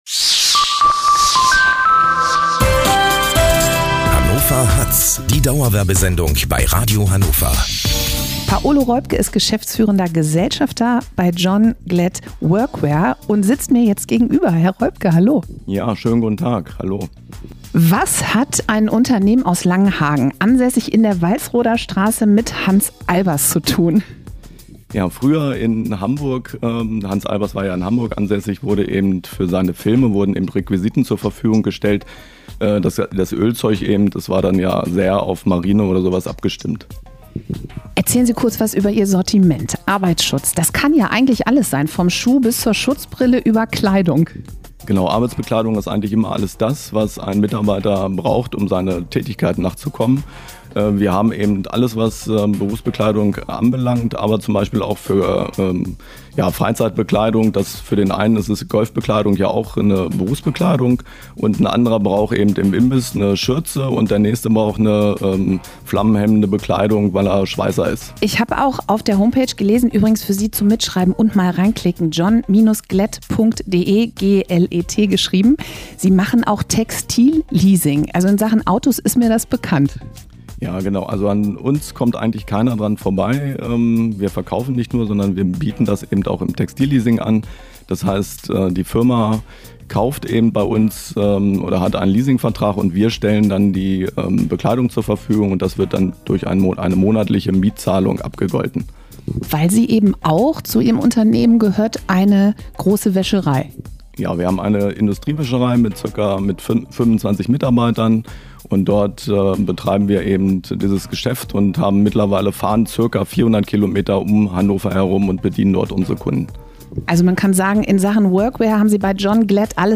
Interview bei Radio Hannover mit uns – natürlich mit Anekdote
Hören Sie gerne rein, unsere Begeisterung für unsere Kunden ist in dem Gespräch spürbar.